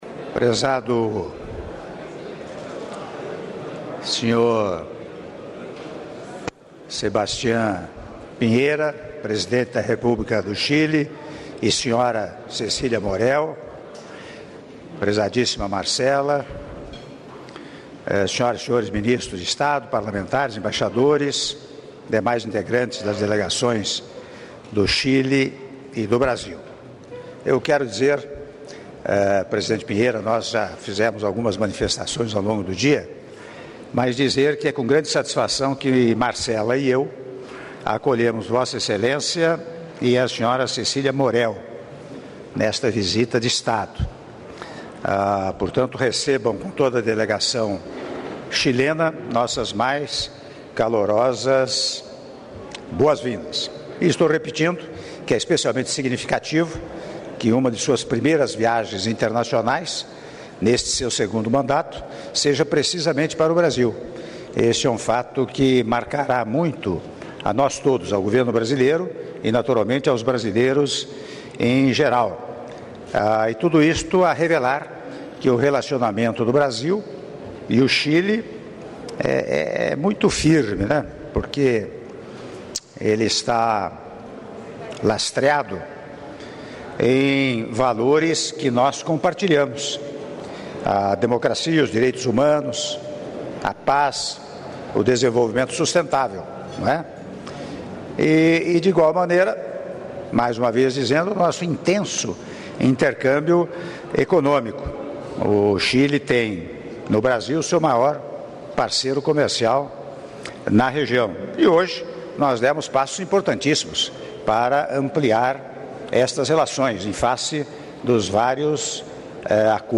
Áudio do brinde do Presidente da República, Michel Temer, durante o almoço oferecido ao Senhor Sebastián Piñera, Presidente da República do Chile e à senhora Cecilia Morel, Primeira-Dama da República do Chile -Brasília/DF- (03min)